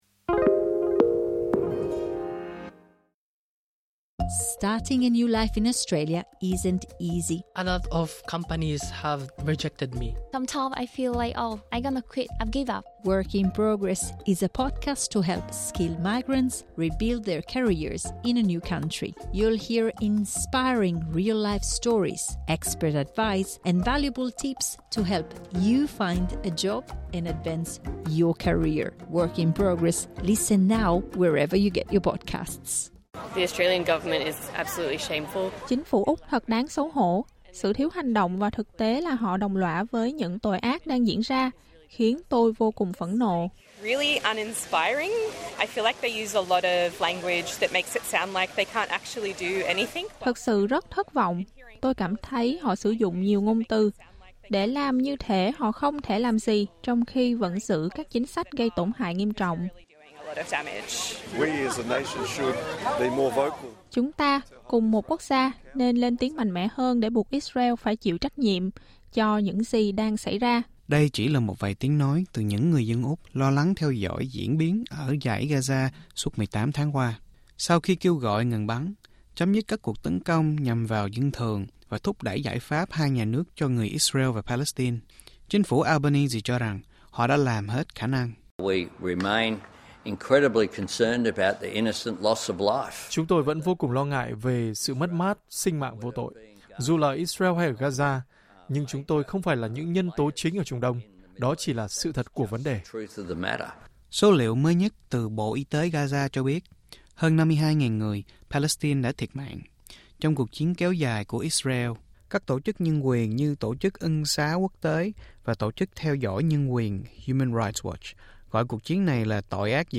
Đây chỉ là một vài tiếng nói từ những người dân Úc lo lắng theo dõi diễn biến ở Dải Gaza suốt 18 tháng qua.